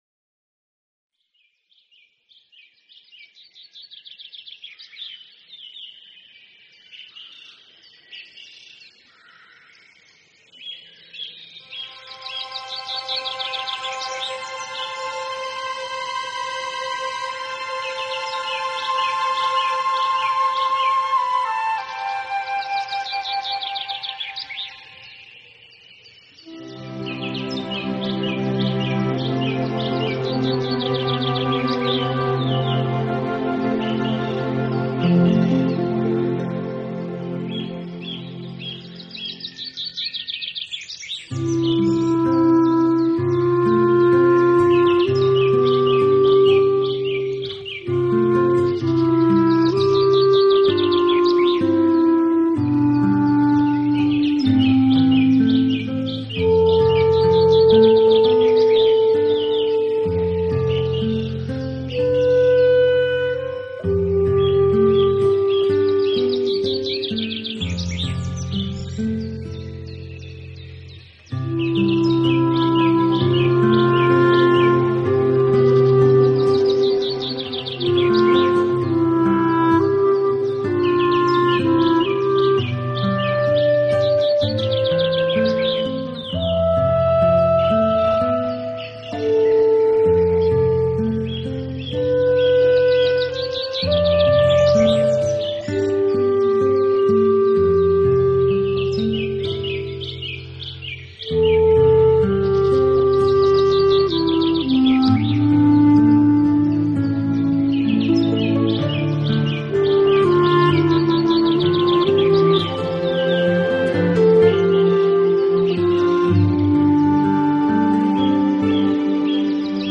【新世纪纯音乐】
特色是以大自然发出的声音如鸟鸣、海豚叫声、海浪声、风声等为主体，配以柔
和，意境深远的旋律，借助了先进的录音技术，产生了非常动人的效果。